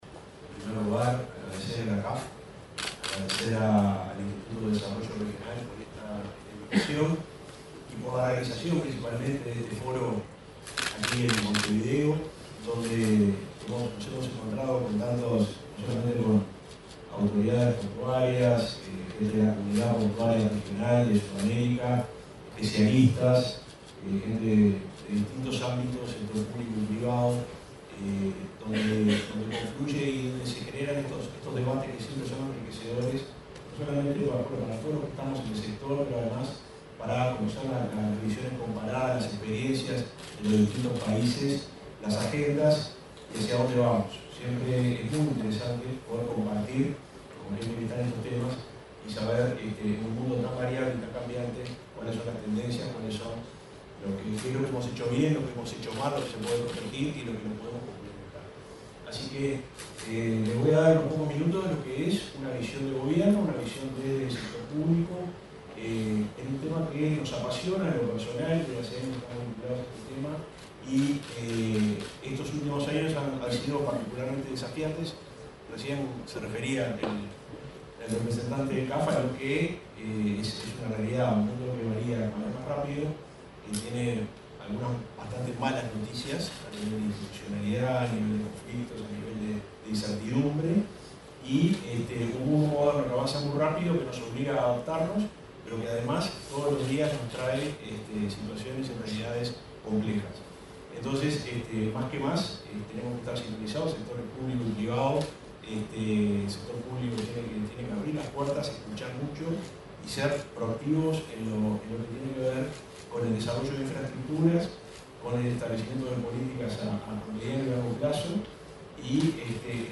Palabras del subsecretario de Transporte, Juan José Olaizola
Palabras del subsecretario de Transporte, Juan José Olaizola 14/08/2024 Compartir Facebook X Copiar enlace WhatsApp LinkedIn El subsecretario de Transporte, Juan José Olaizola, participó, este miércoles 14, en la sede del CAF - Banco de Desarrollo de América Latina y el Caribe, en la apertura del primer Foro Fluvial Sudamericano.